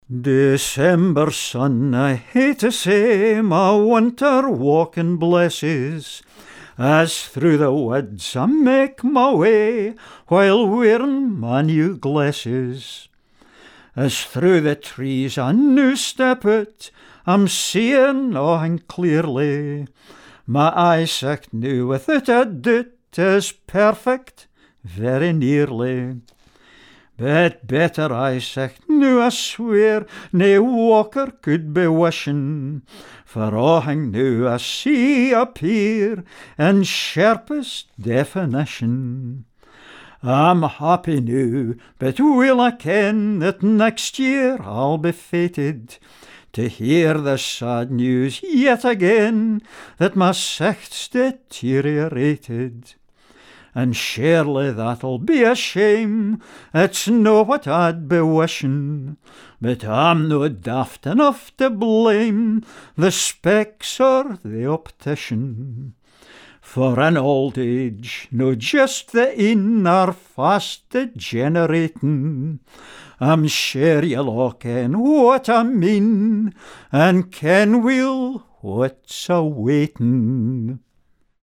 Scots Song